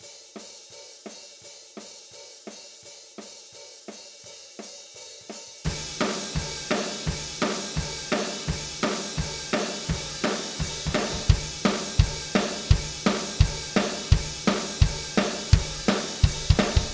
Ich halte die Overhead-Mikrofone für relativ essentiell beim Schlagzeug und erst recht wenn es um einen "echten Klang" geht.
Im Beispiel: erst nur Closed Miks (Hihat), dann nur OH, Closed+OH, nur Room, alles zusammen Dein Browser kann diesen Sound nicht abspielen.